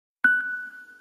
장르 효과음